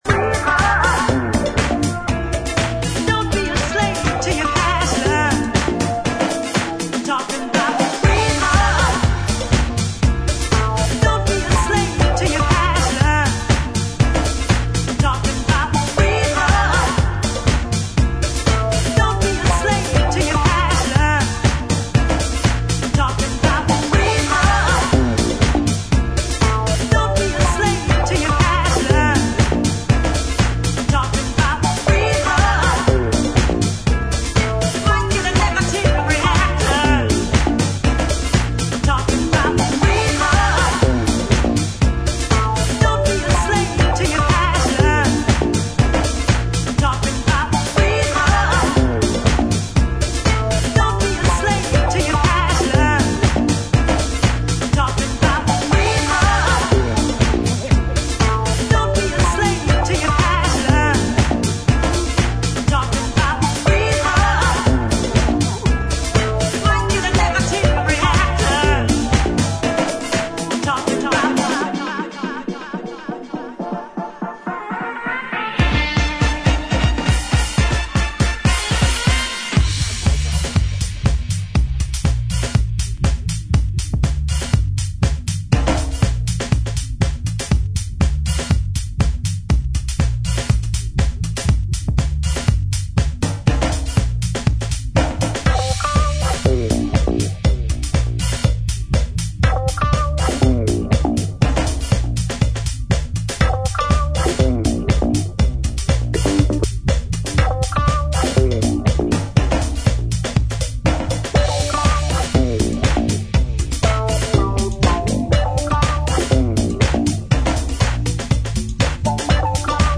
ジャンル(スタイル) CLUB / DANCE / NU DISCO / HOUSE / DISCO